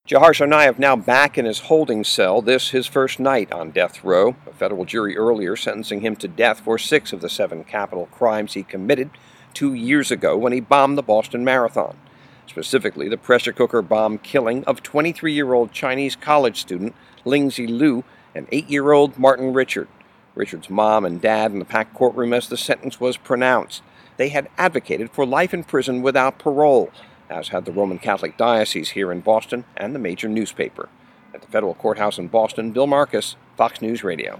LIVE 9PM –